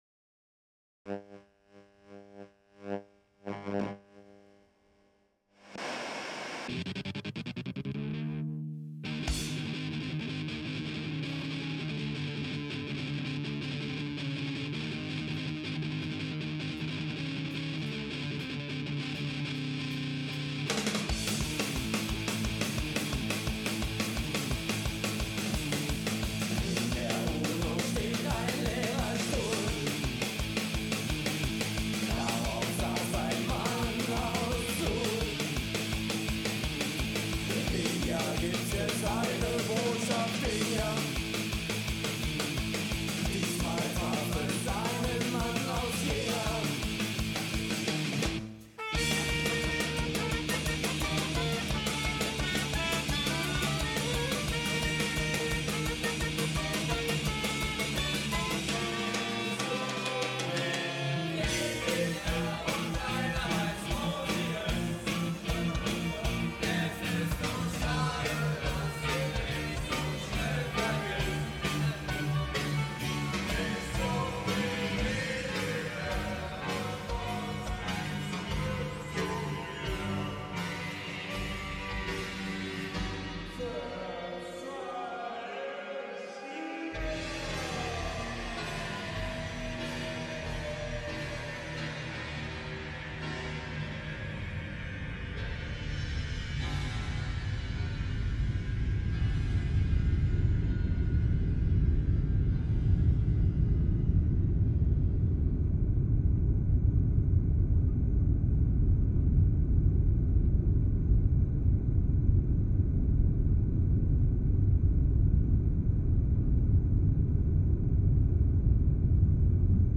2024_Tränenpalast "Palace of Tears"_this piece was composed for the 192 speaker wave-field soundsystem installed at the New Music Lab in Amare for the WFS Festival. The piece centers around the former border-crossing point between East and West Germany at Berlin’s Friedrichstraße.
The piece weaves together music from musicians that performed there between 1993 and 2004 with personal recordings of the many train rides made between Berlin and The Hague in 2023 and 2024.